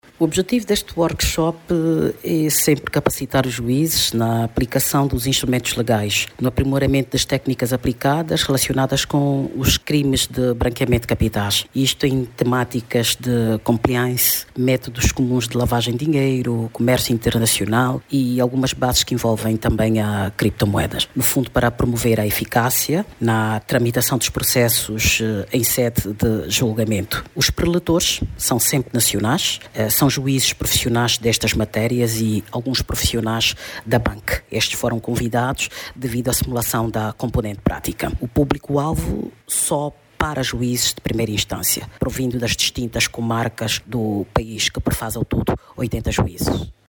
Promovida pelo Conselho Superior da Magistratura Judicial, a formação é direcionada a juízes de primeira instância conforme avançou a juíza desembargadora Odeth Ipanga.